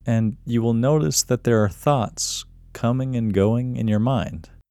QUIETNESS Male English 4
The-Quietness-Technique-Male-English-4.mp3